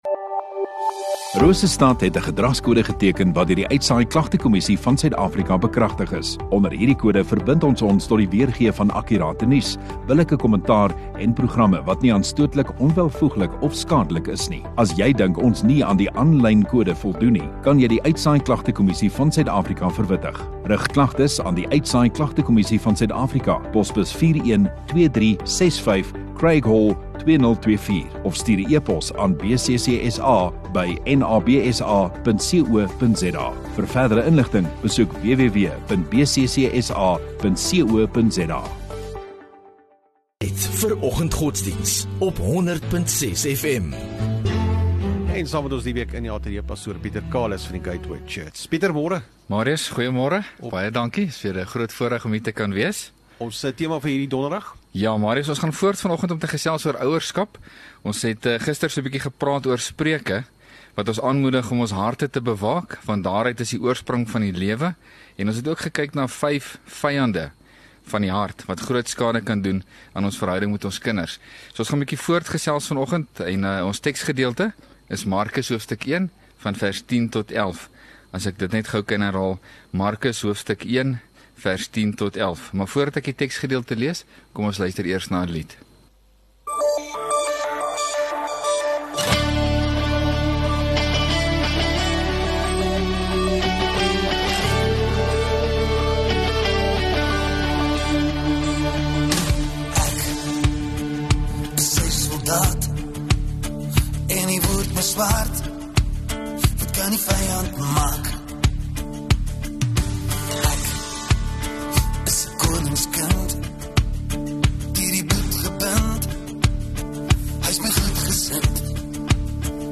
30 Jan Donderdag Oggenddiens